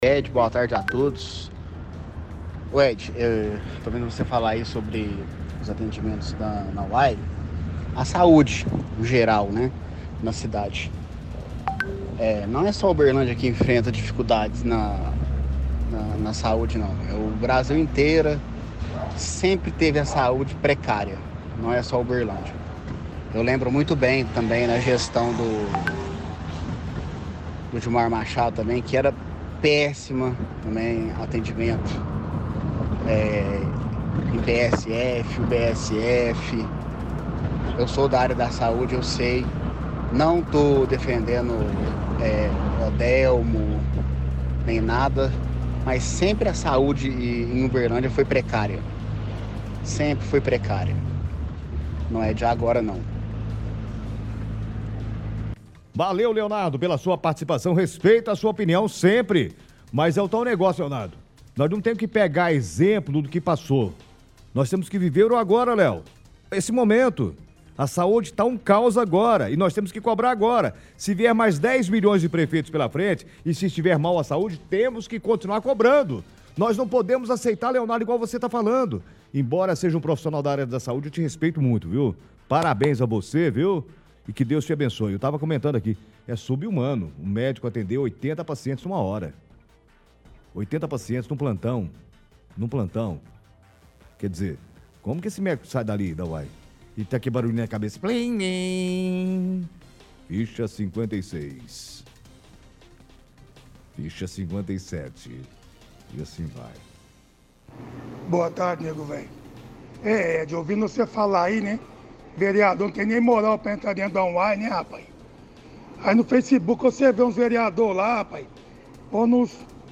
Ligação Ouvintes
Ligação-Ouvintes.mp3